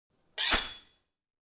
SwordSoundPack
SWORD_19.wav